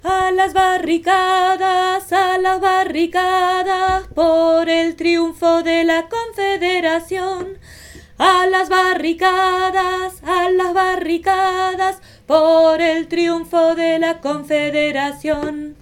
Negras_tormentas_2eme_voix_refrain.mp3